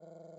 Toad [flying].wav